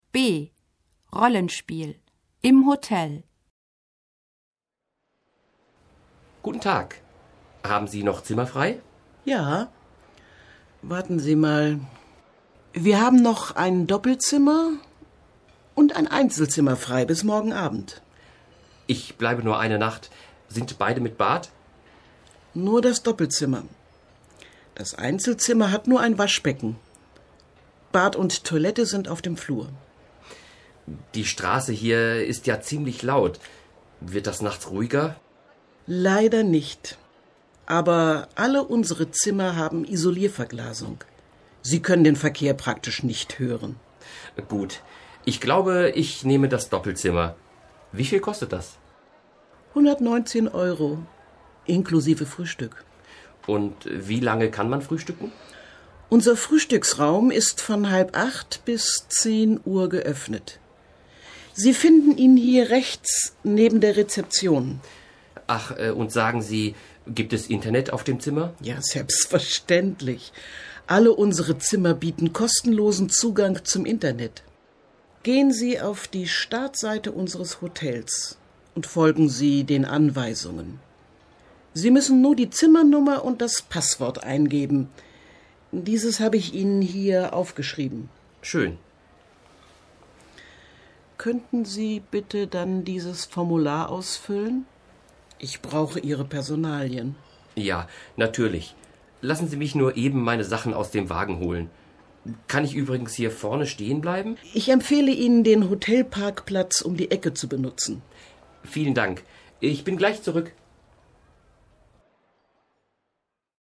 Rollenspiel: Im Hotel (1818.0K)